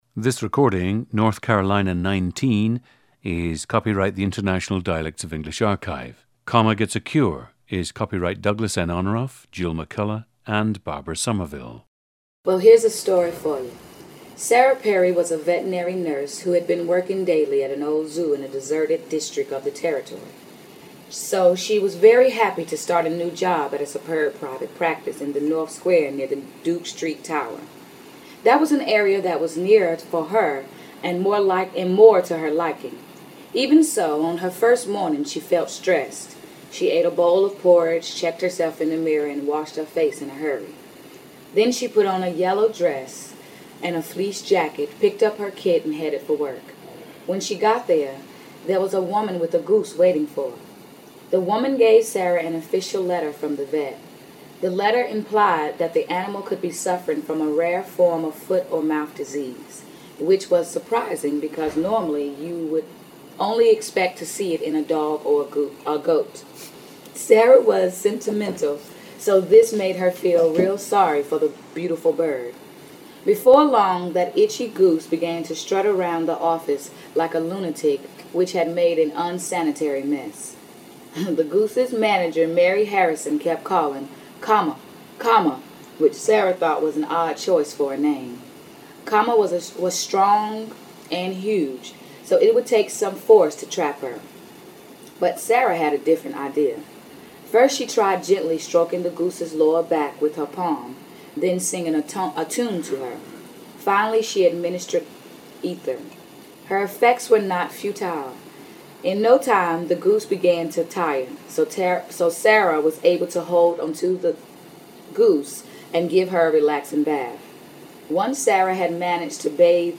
GENDER: female
ETHNICITY: African-American
Residing in the Western United States for two years has had minimal impact on her dialect. She has, however, been able to shift rather successfully to General American speech or other dialects when necessary. The dialect is rich with Southern features including: 1) An occasional reduction/elimination of /r/ coloration, particularly in unstressed vowel endings of words such as mother, father and brother. 2) Frequent contraction of /ing/ endings to /in/ (goin’, comin’, etc.) 3) Modification of final “l” sound in words such as all and call, to a lip-rounded sound where the tongue makes no motion toward the alveolar ridge; sometimes the /l/ is eliminated altogether. 4) Words with the short /e/ sound, as in DRESS, are often pronounced with the short /i/, as in KIT. 5) Clear reduction of the diphthong in the PRICE set to the single “cardinal 4” vowel. 6) Occasional substitution of /f/ for /th/ in word endings.
The recordings average four minutes in length and feature both the reading of one of two standard passages, and some unscripted speech.